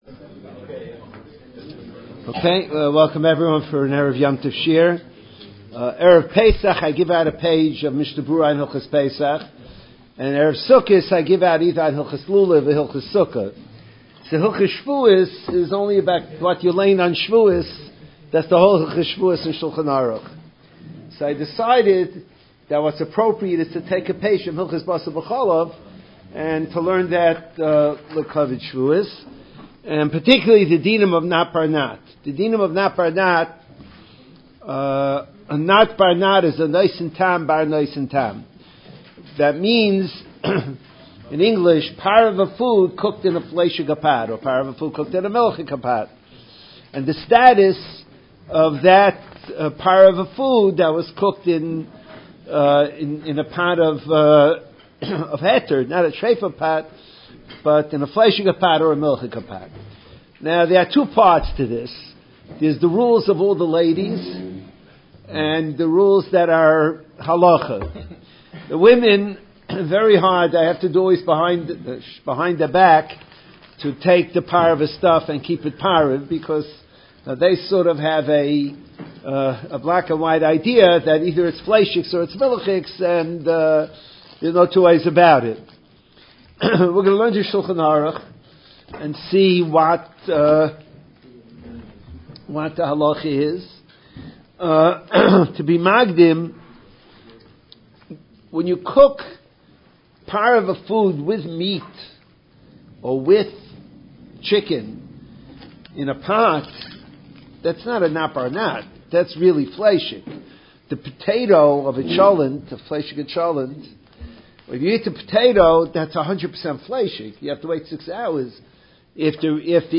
Erev Shavuos Shiur on Basar VeCholov 2025.mp3